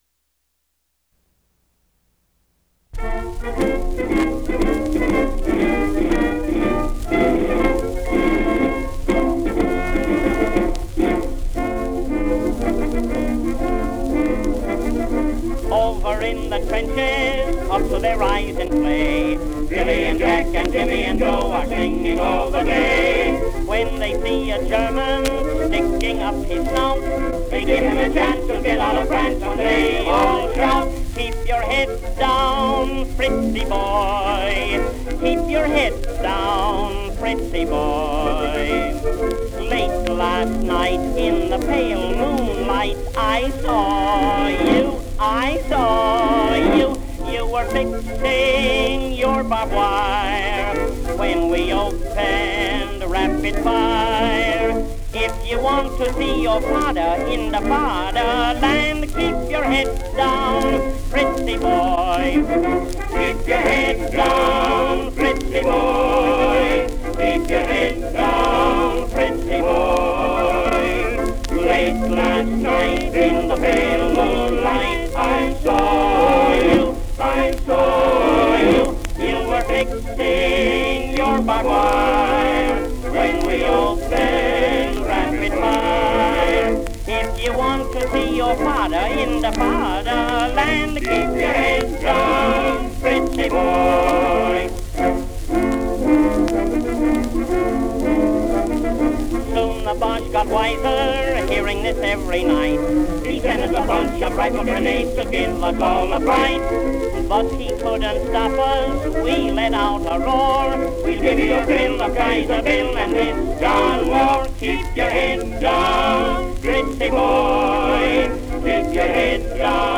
World War I song